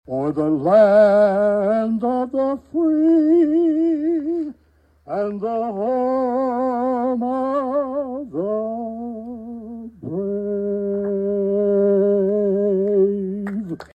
All Veterans Tribute activities got off to an early start with the 20th annual fundraising golf tournament held Saturday at the Emporia Golf Course.
3512-national-anthem.mp3